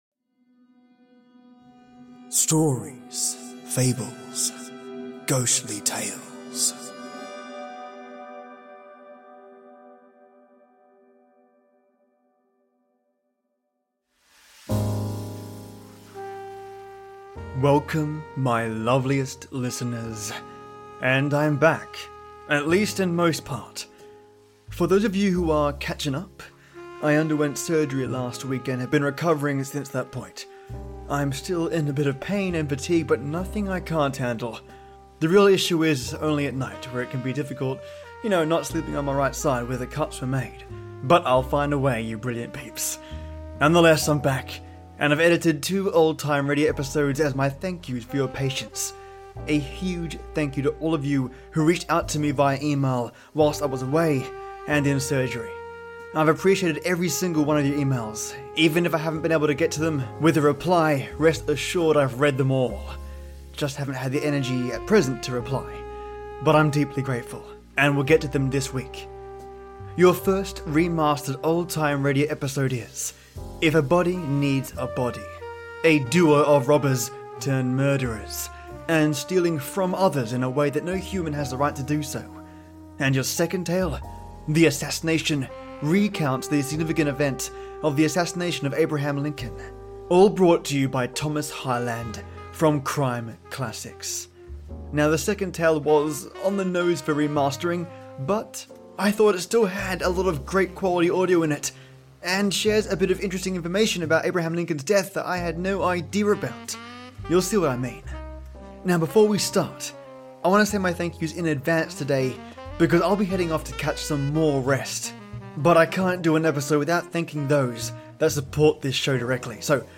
Remastered CRIME CLASSICS: If a body needs a Body & The Assassination 📻📻